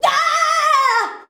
YEAEEAAHHH.wav